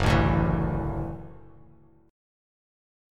F#m7#5 chord